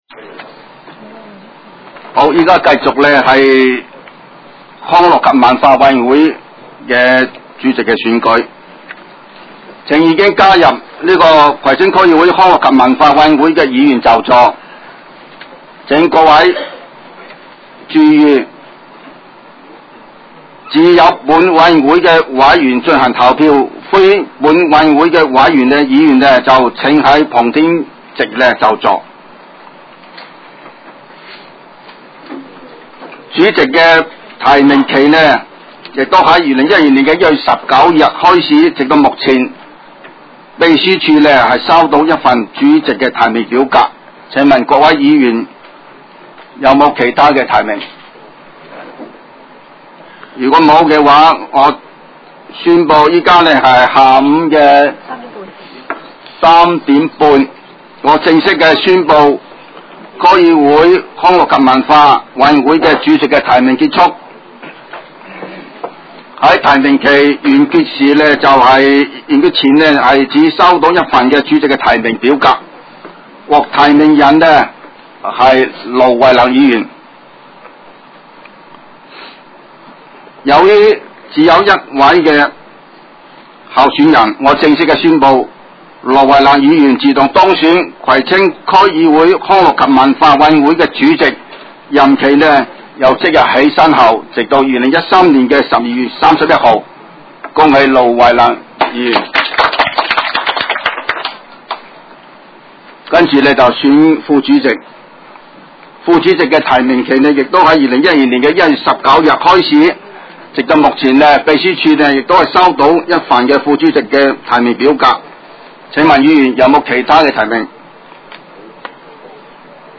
委员会会议的录音记录
地点: 香港葵涌兴芳路166-174号 葵兴政府合署10楼 葵青民政事务处会议室